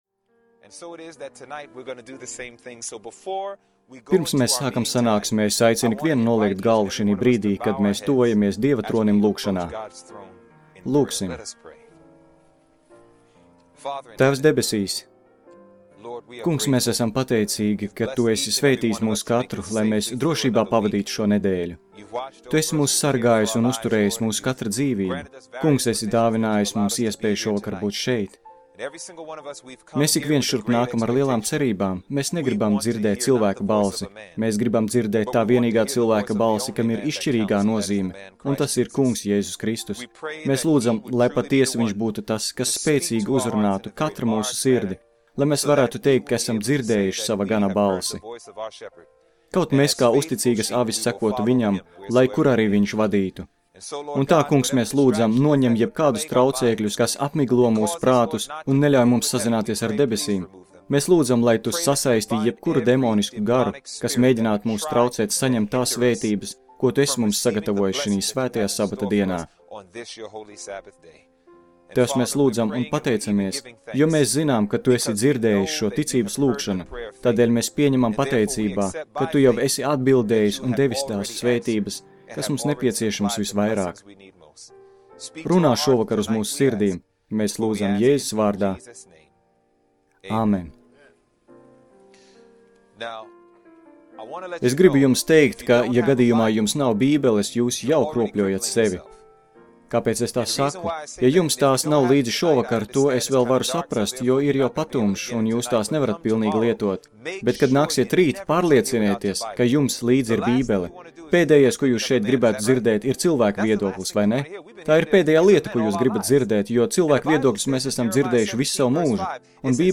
Seminārs - Pārliecība